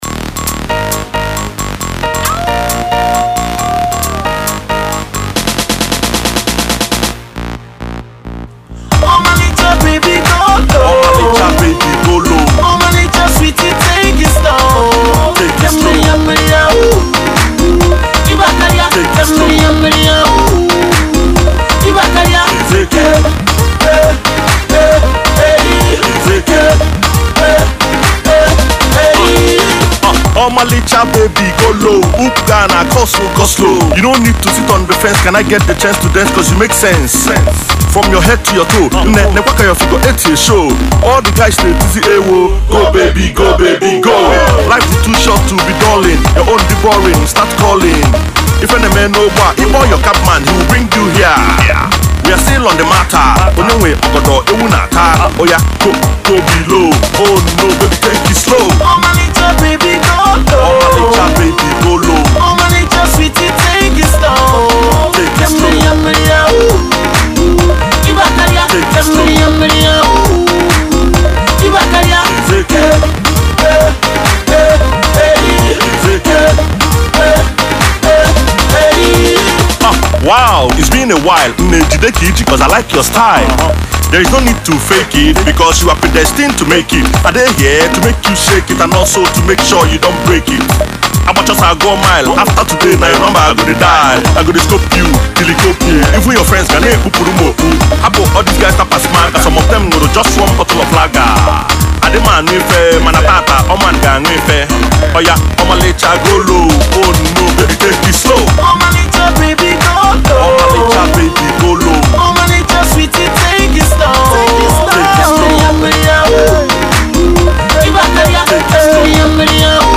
doing due justice to the uptempo track